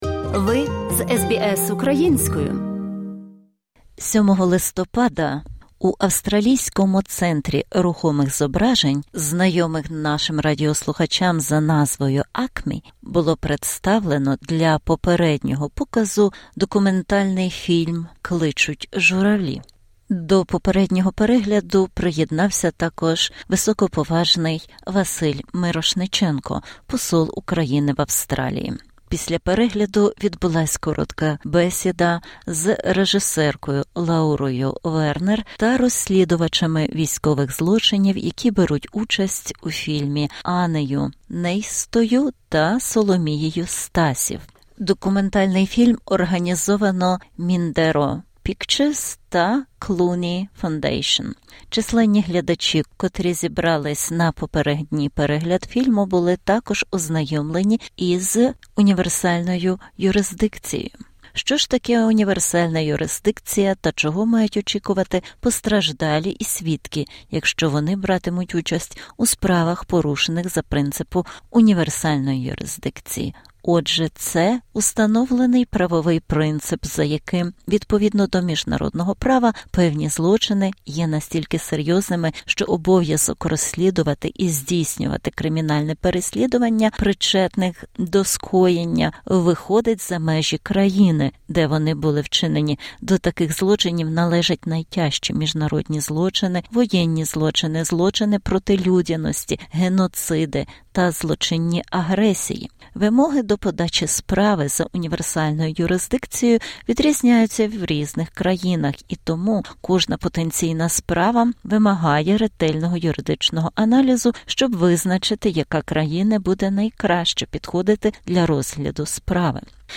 Інтерв’ю з високоповажним послом України в Австралії, Василем Мирошниченком, про фільм "Клич журавлів"